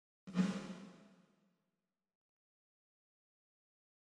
et ici la source est encore plus loin :